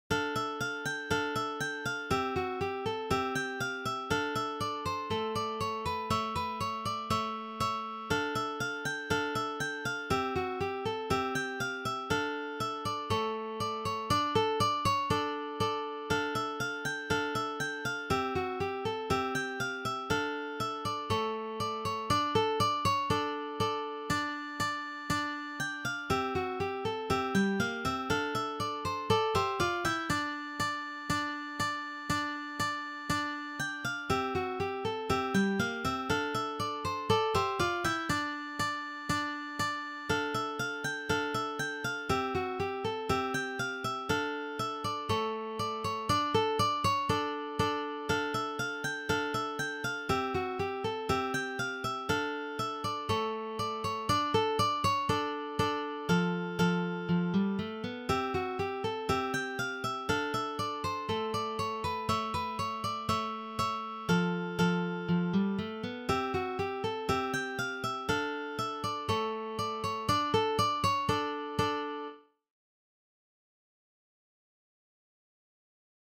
for three guitars
This is from the Baroque period.